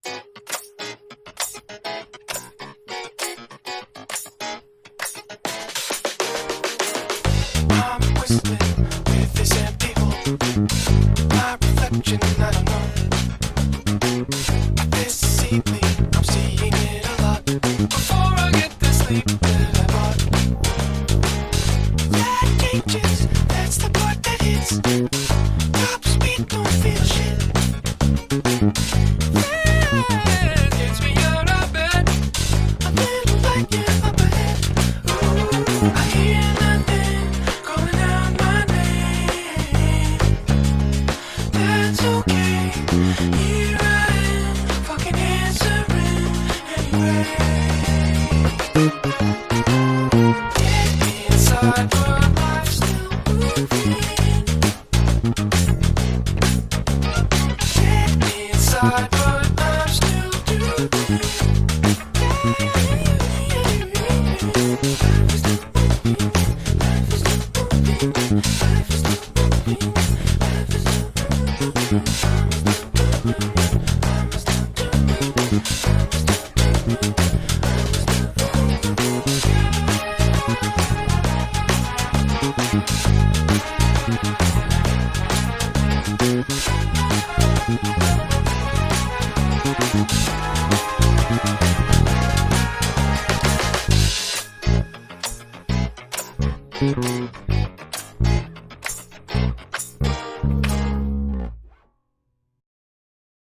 Jedná se vzhledem o obyčejnou P bass, avšak s neobvyklou kombinací dřev a pár věcmi udělanými jinak.
Kdybych to měl popsat tak, jak slyším, tak to fakt hrne, ale opravdu moc příjemně smile.